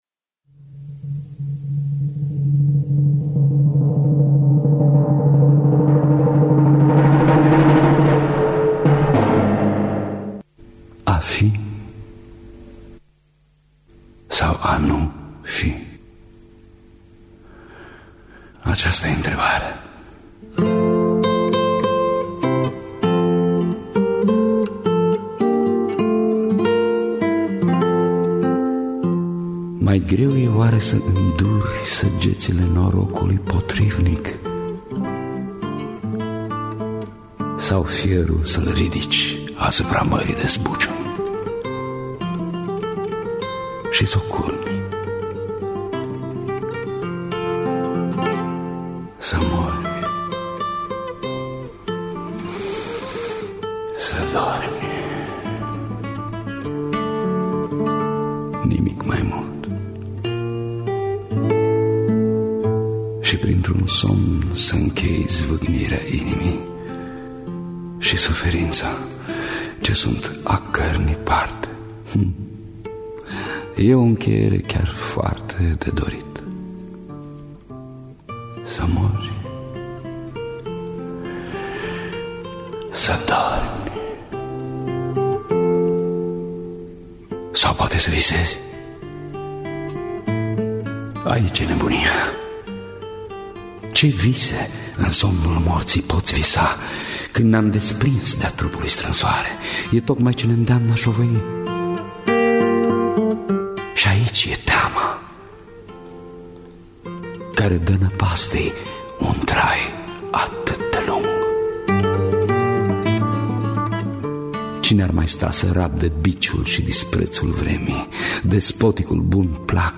– Teatru Radiofonic Online
Efecte percuţie